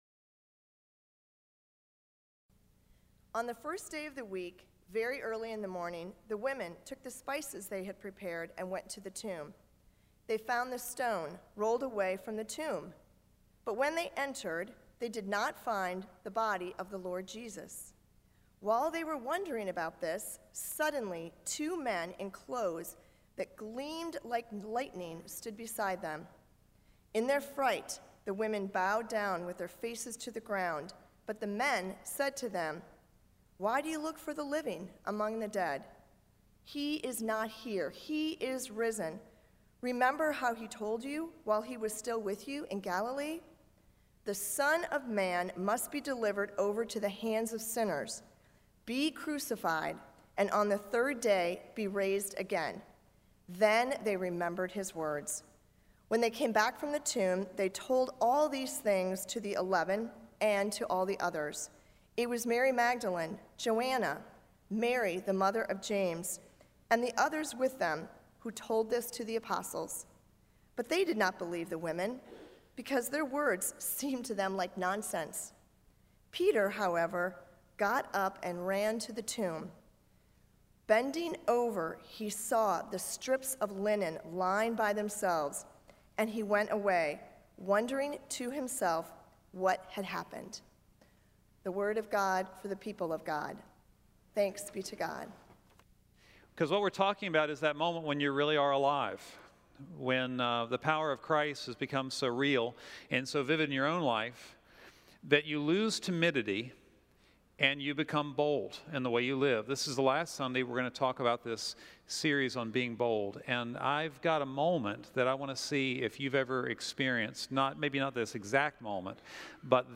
sermon8-25-13.mp3